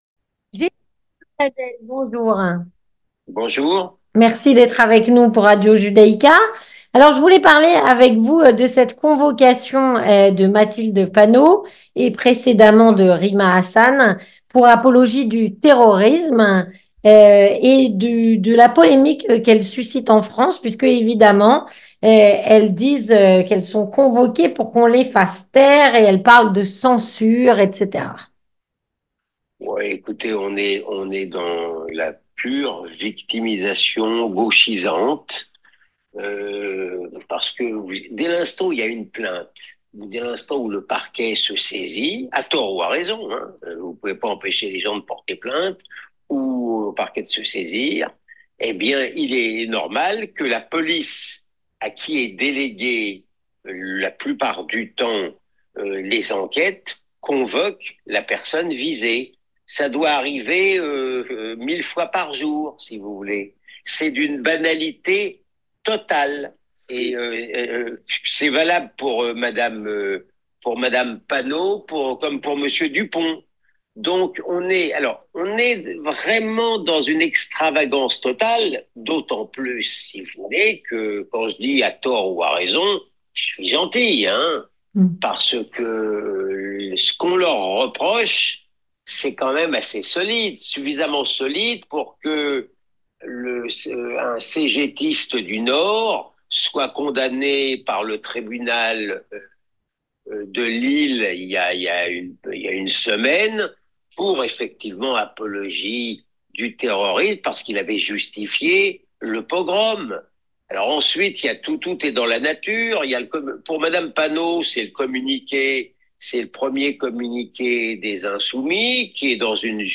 Avec Gilles-William Goldnadel, avocat, essayiste, président d’Avocats sans frontières, auteur de “Journal de guerre”